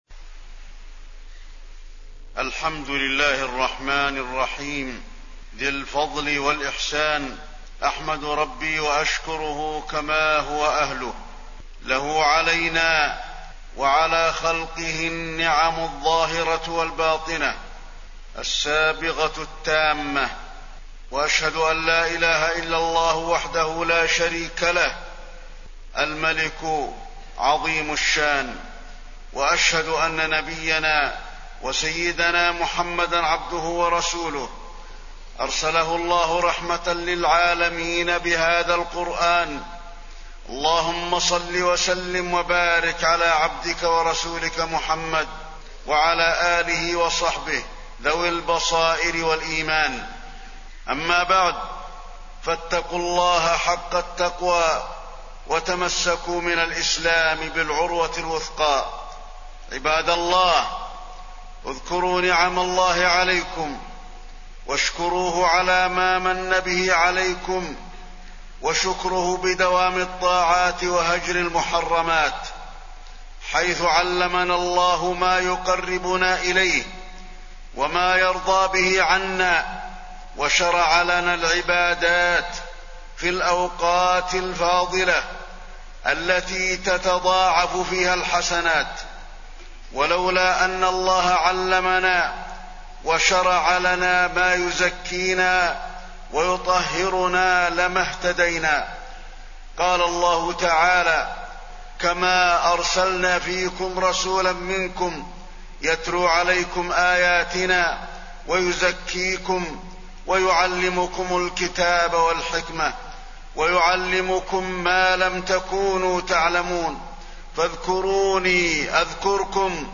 تاريخ النشر ٧ رمضان ١٤٣٠ هـ المكان: المسجد النبوي الشيخ: فضيلة الشيخ د. علي بن عبدالرحمن الحذيفي فضيلة الشيخ د. علي بن عبدالرحمن الحذيفي شهر الإحسان